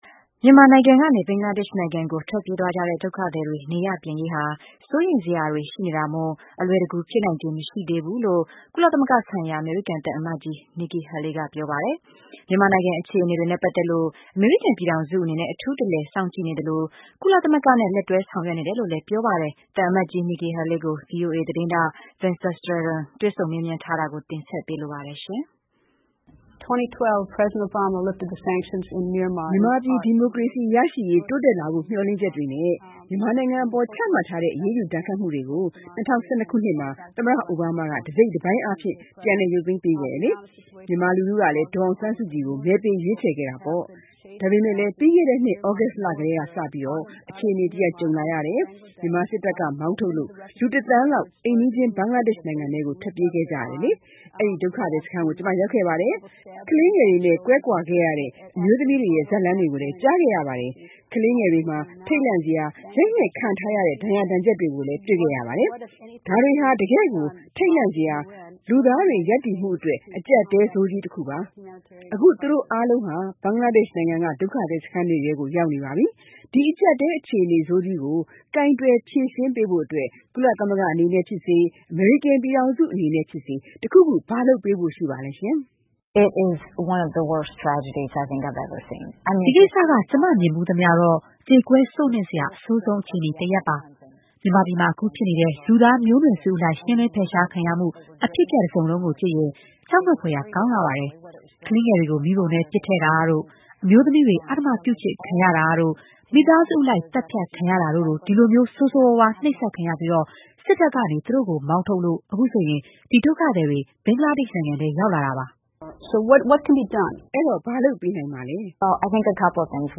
ကုလသမဂ္ဂဆိုင်ရာ အမေရိကန်သံအမတ်ကြီး Nikki Haley ကို Greta Van Susteren က နယူးယောက်မှာ တွေ့ဆုံမေးမြန်း။ ( ဇန်နဝါရီ ၁၇-၂၀၁၈)
ကုလသမဂ္ဂဆိုင်ရာ အမေရိကန်သံအမတ်ကြီး Nikki Haley ကို Van Susteren ကဗွီအိုအေ အတွက် တွေ့ဆုံမေးမြန်းထားတာဖြစ်ပါတယ်။